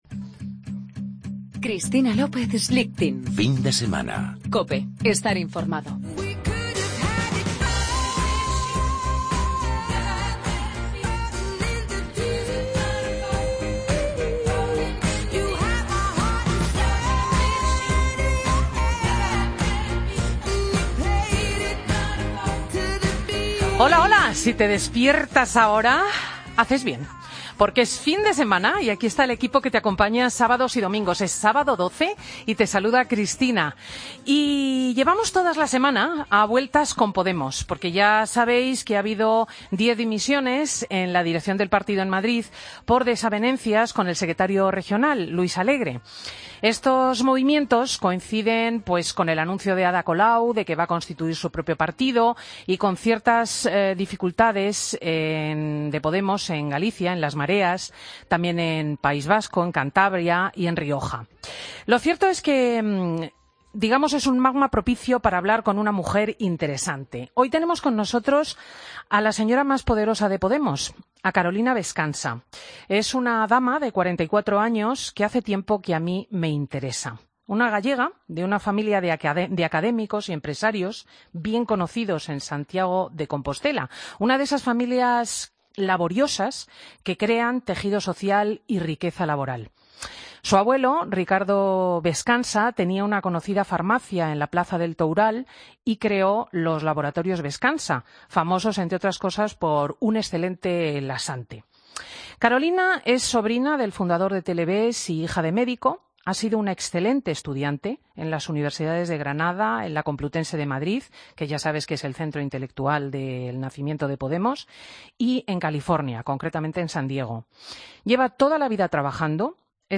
Escucha la entrevista a Carolina Bescansa en Fin de Semana COPE